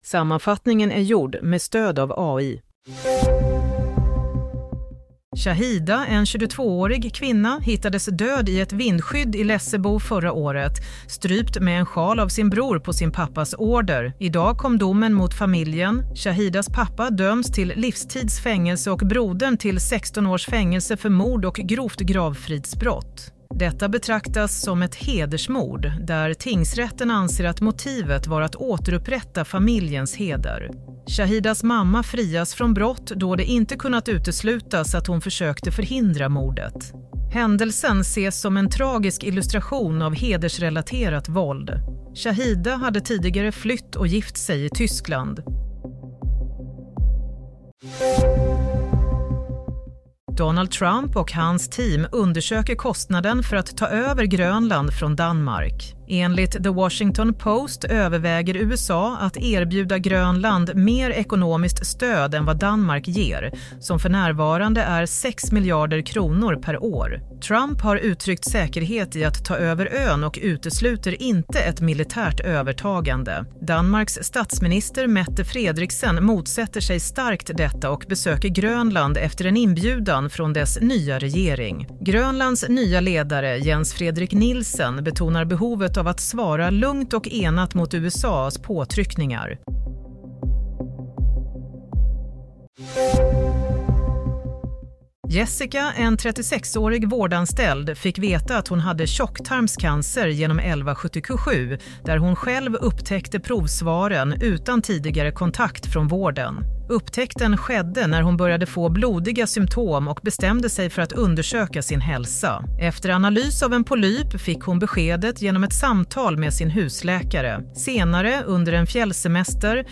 Nyhetssammanfattning 2 april – 16:00